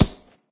plasticgrass3.ogg